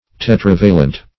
Search Result for " tetravalent" : Wordnet 3.0 ADJECTIVE (1) 1. haveing a valence of four ; The Collaborative International Dictionary of English v.0.48: Tetravalent \Te*trav"a*lent\, a. [Tetra- + L. valens, -entis, p. pr.]